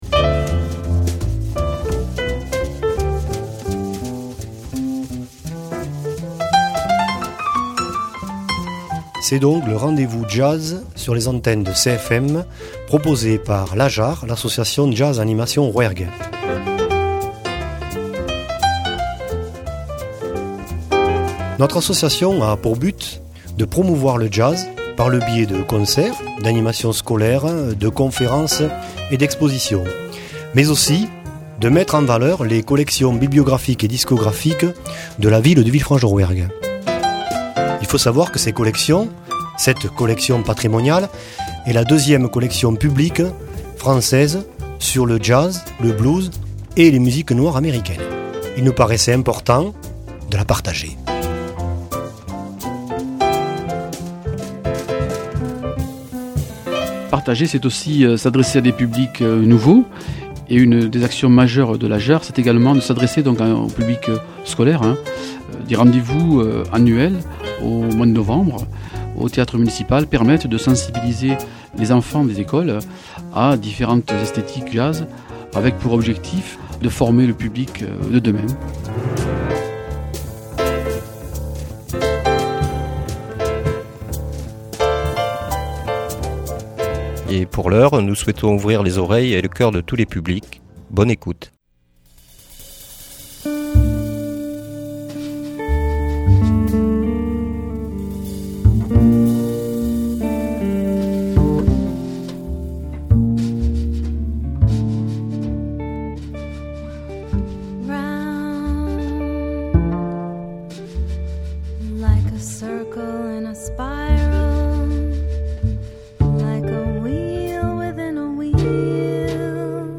Une heure de jazz envoûtant, passant des rythmes les plus frénétiques aux plus douces caresses musicales.